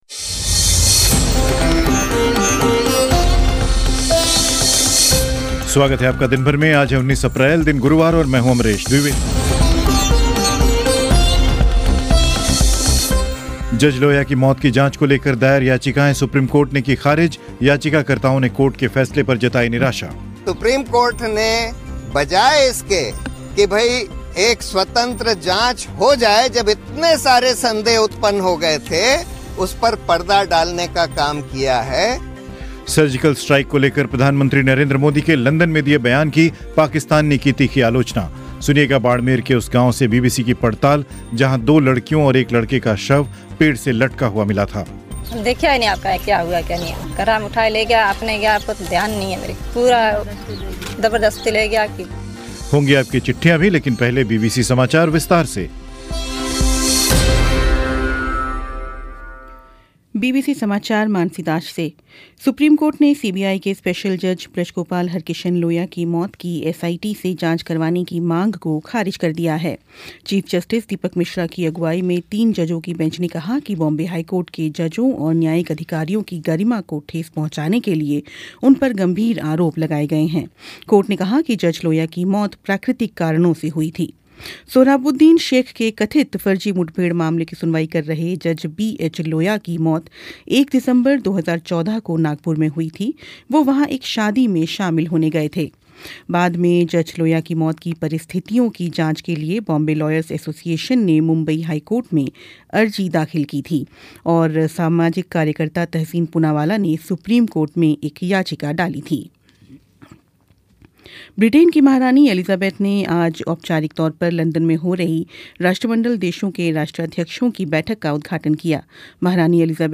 सुनिए बाड़मेर के उस गांव से बीबीसी की ख़ास रिपोर्ट जहां दो लड़कियों और एक लड़के का शव पेड़ से लटका हुआ मिला था...